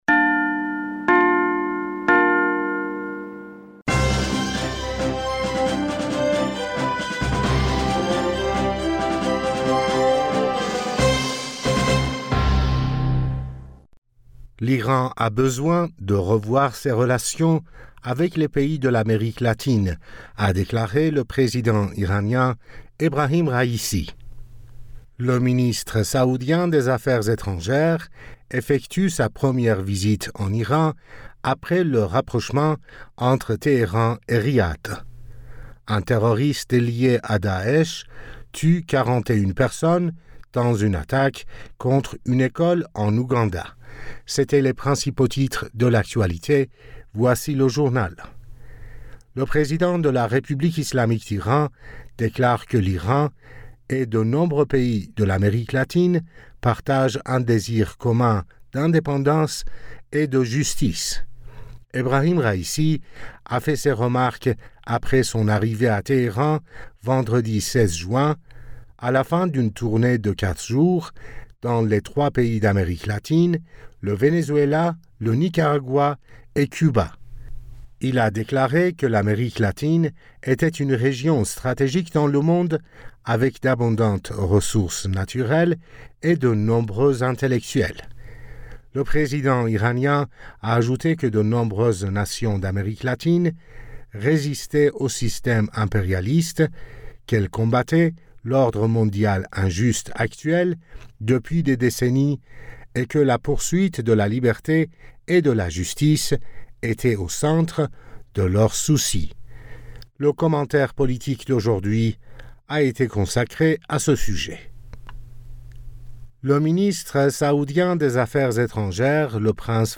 Bulletin d'information du 17 Juin 2023